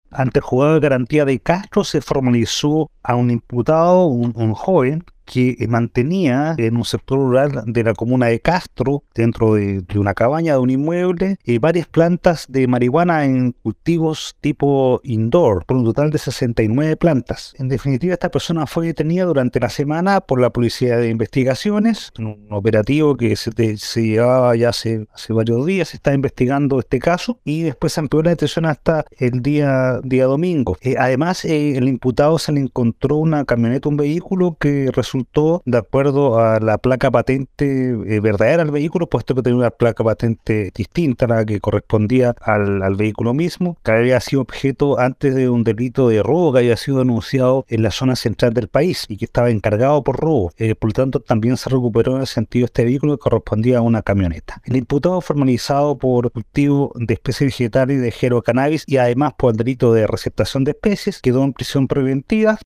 Respecto a la formalización del imputado se refirió el Fiscal de Castro, Javier Calisto: